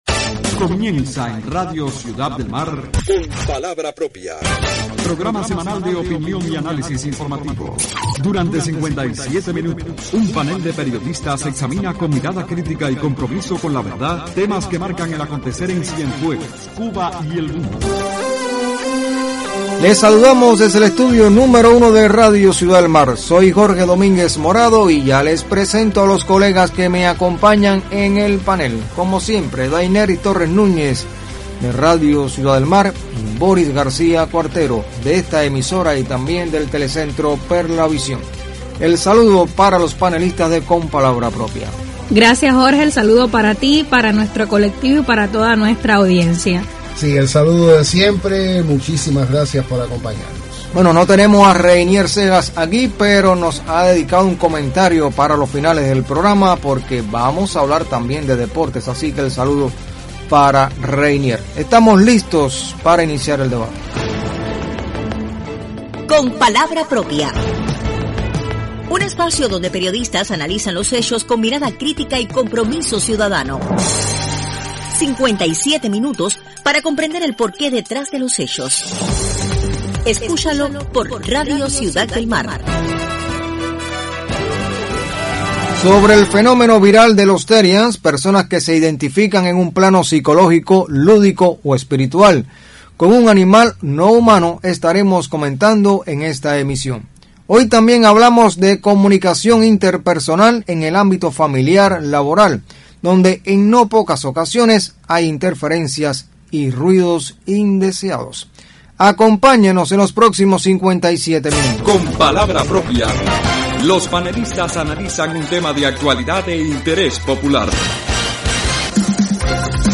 Los therians, personas que se identifican con un animal no humano, es un fenómeno que se ha vuelto viral en redes sociales y desatan la polémica. Los panelistas de Con palabra propia también se suman a este debate en la emisión del 28 de febrero del programa líder de opinión y análisis informativo en la radio de Cienfuegos.